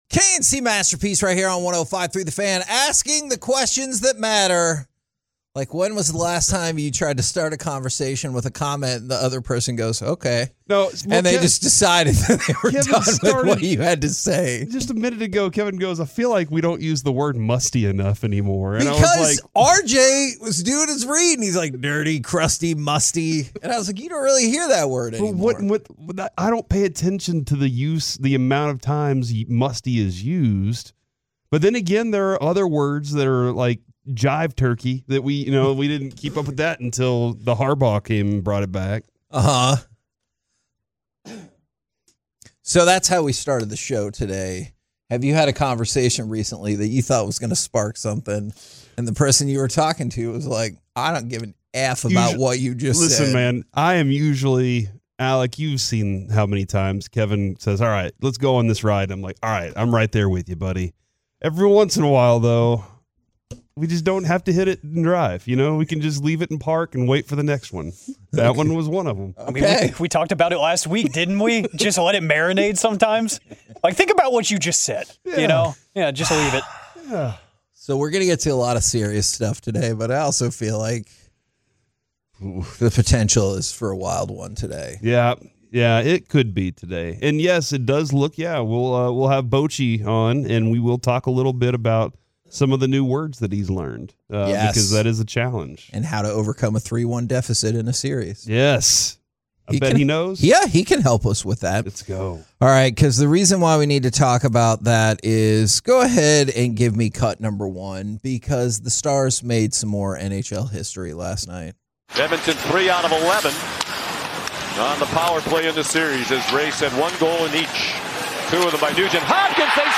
The conversation provides a fascinating glimpse into the world of elite marathon running from local DFW athletes.